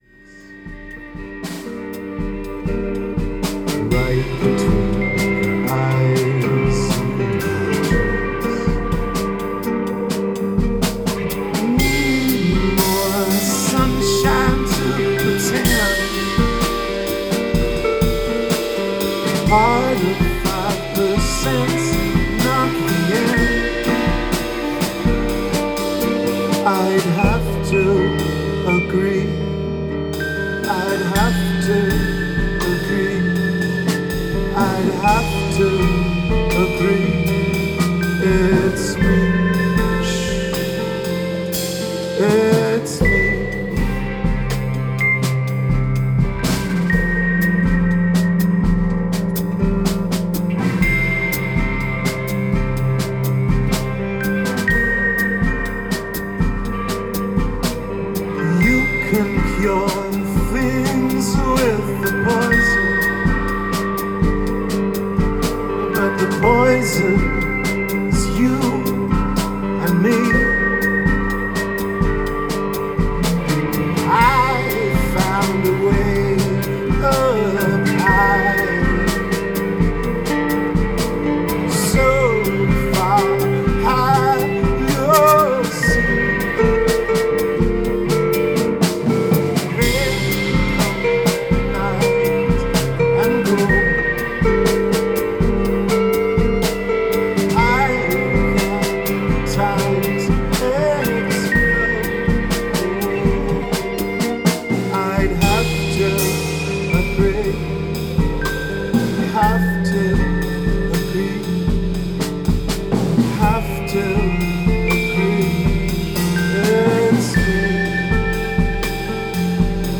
Rehearsals 16.8.2013